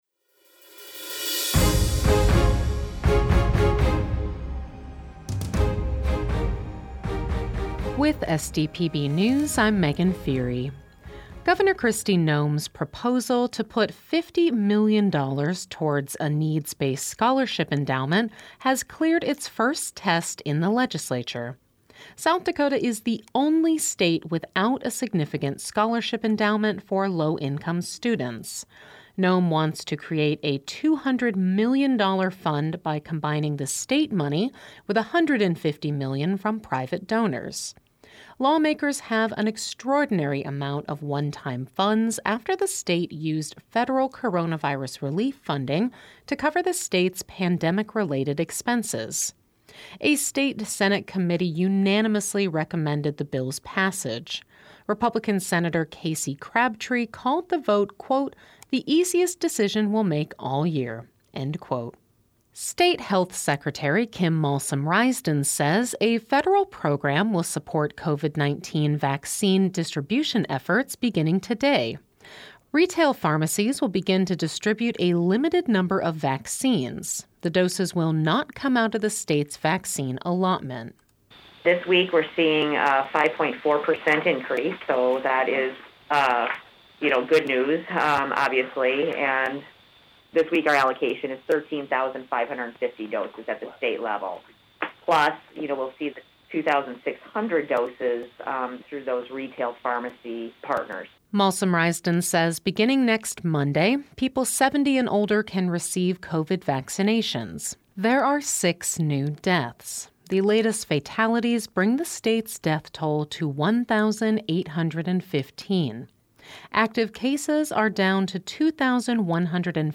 Each day, SDPB's journalism team works to bring you pertinent news coverage. We then compile those stories into one neatly formatted daily podcast so that you can stay informed.